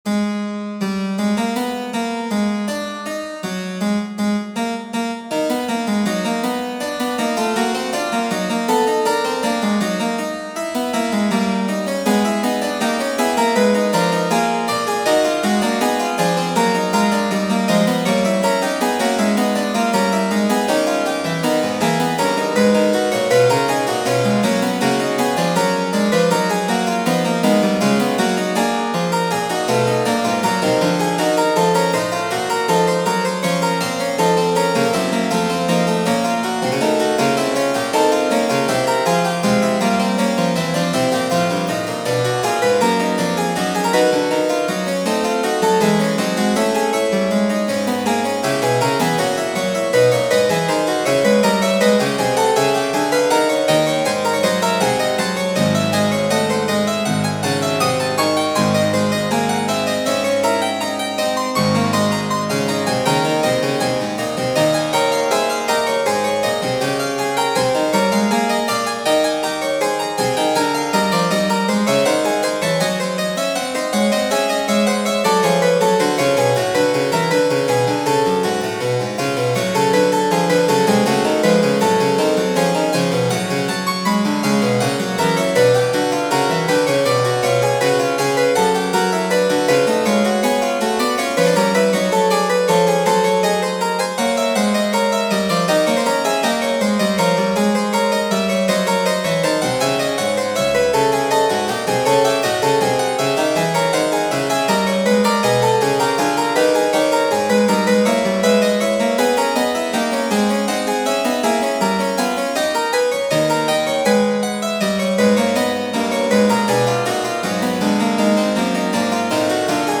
in Piano Music, Solo Keyboard
Note: the whole composition has been rendered in A = 415 tuning and 1/5th-comma meantone temperament, in order to better, more accurately capture the Baroque essence this piece strives to convey.
The original key of G sharp minor has such a characteristic mystical and gloomy mood reminding me somewhat at Halloween – and interestingly your „Halloween-fugue“ which I have seen on YouTube, too, is consequently also in that key!
While Bach’s one has a more walking character, your one has a continuous flow due to the complementary rhythm created by the semiquavers in the counterpoint and the episodes.